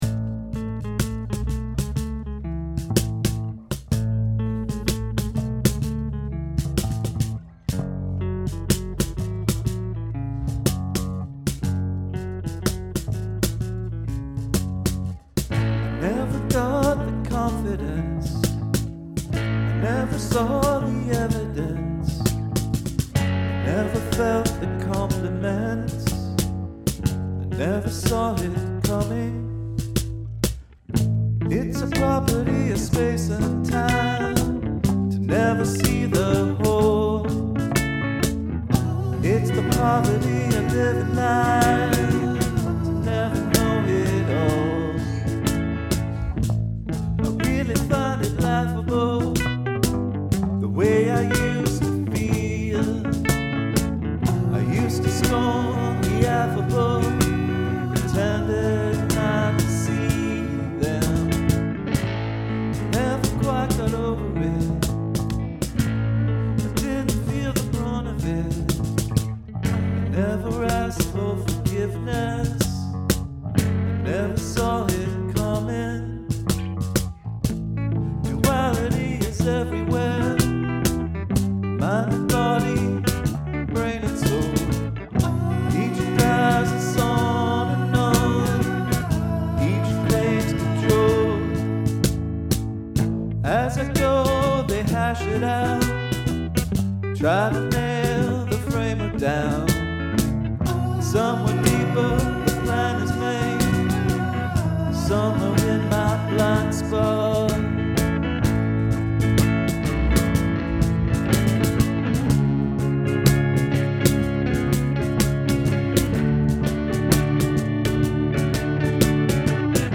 Hand percussion
The parts seem different, and the melody is more sure.